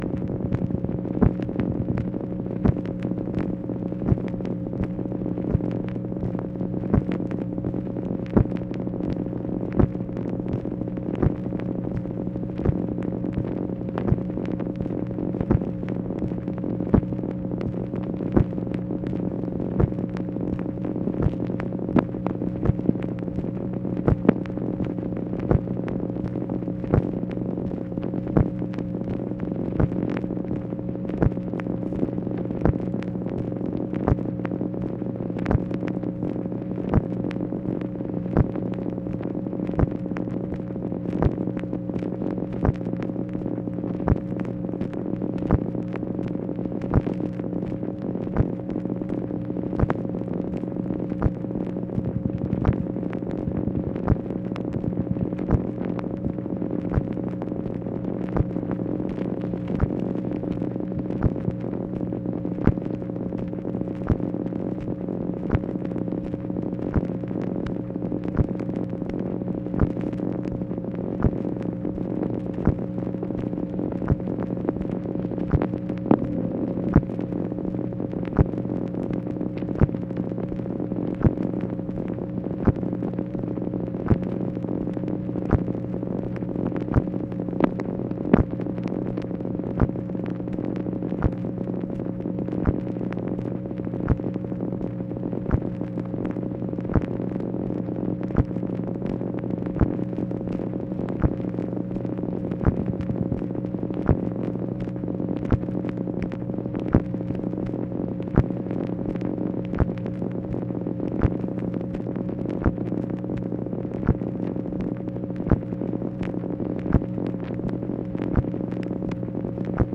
MACHINE NOISE, June 15, 1965
Secret White House Tapes | Lyndon B. Johnson Presidency